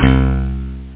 Amiga 8-bit Sampled Voice
stringz.mp3